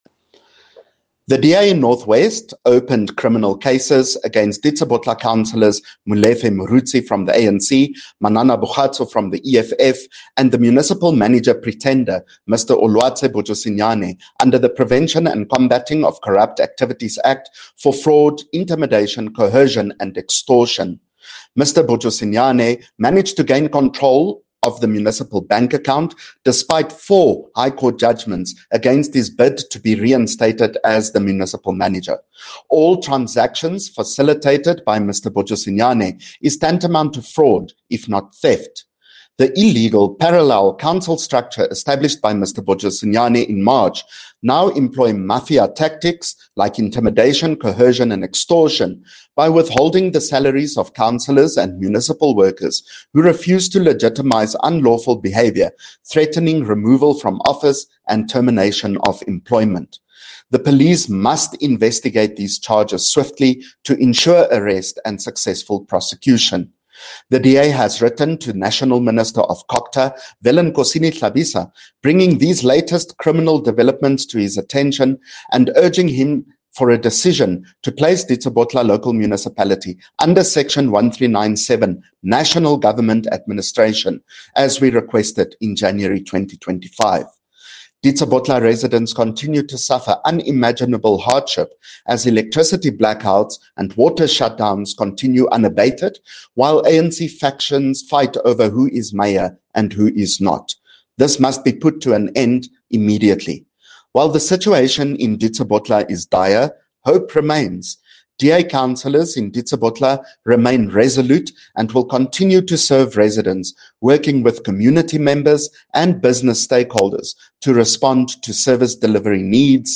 Note to Broadcasters: Please find linked soundbites in
English and Afrikaans by CJ Steyl MPL.